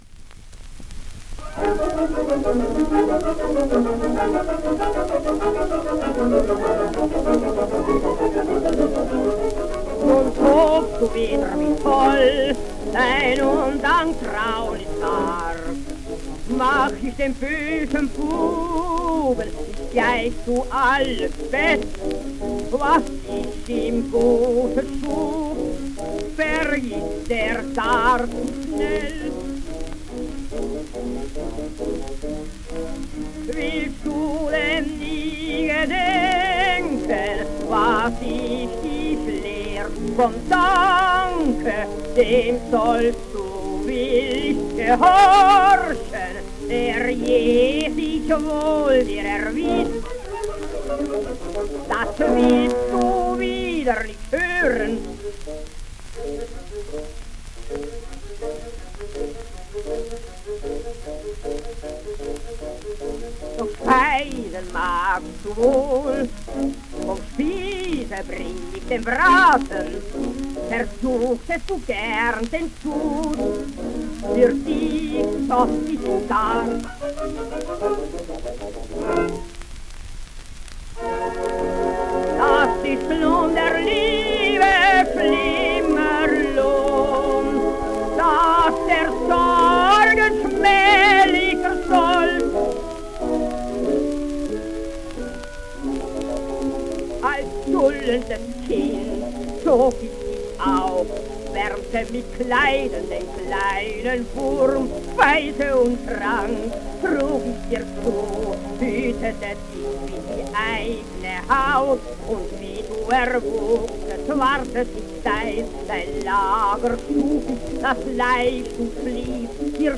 Hans Bechstein singsSiegfried: